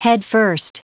Prononciation du mot headfirst en anglais (fichier audio)